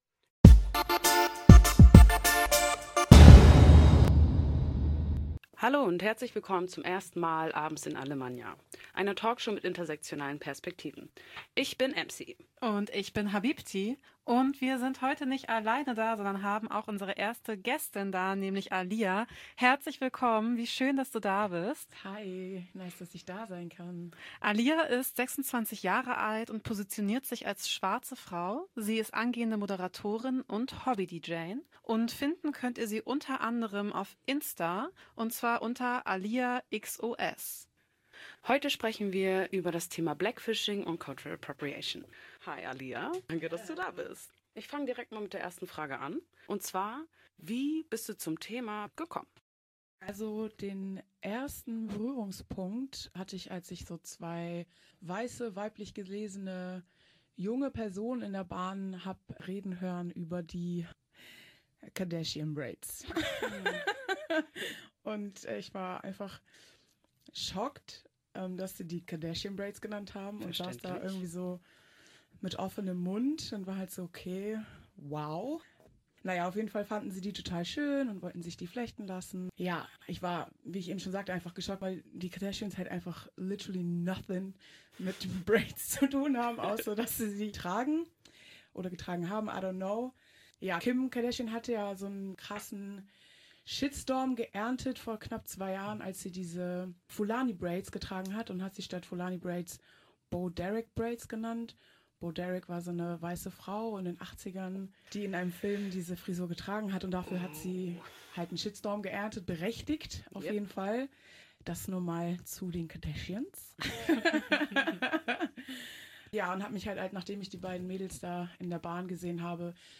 Eine Talkshow mit intersektionalen Perspektiven